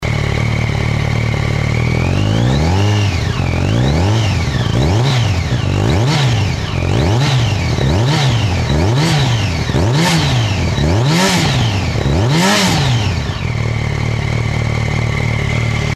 como suena la fazer 1000 uffffffffff